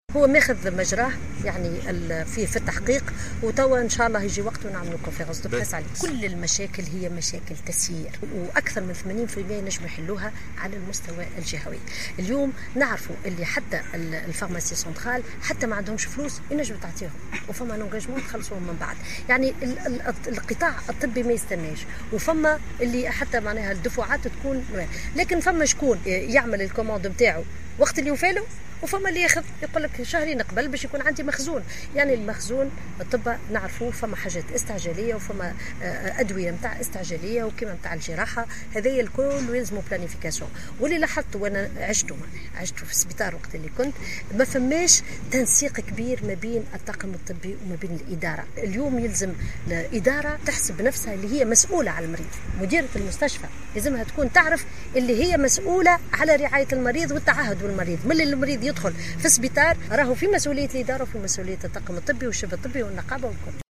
أعلنت وزيرة الصحة سميرة مرعي خلال زيارة أدتها اليوم السبت 3 سبتمبر 2016 إلى المستشفى الجامعي الطاهر صفر بالمهدية أنه سيتم اتخاذ جملة من الإجراءات العاجلة لتحسين الخدمات الصحية في الجهة والتي مازالت دون المطلوب وفق تعبيرها.